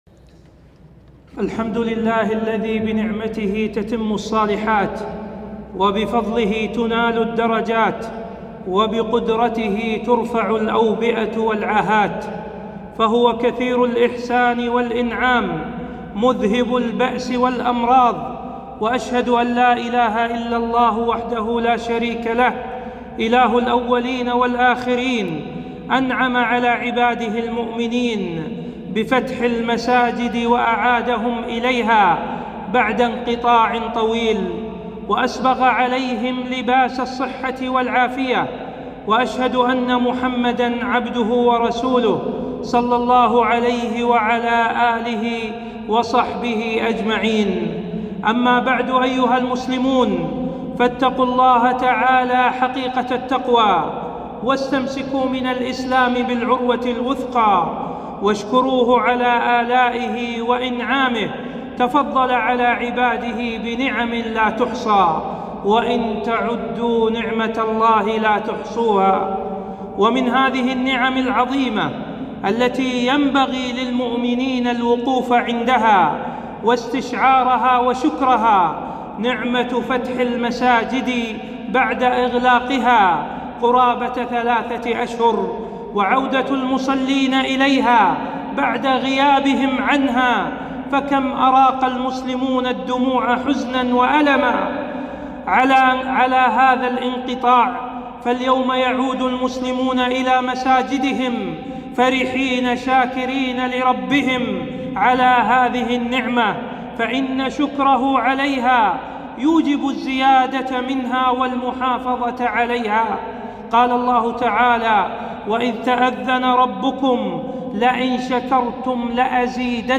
خطبة - افتتاح المساجد بعد غلقها بسبب كورونا فضل وأعمال عشر ذي الحجة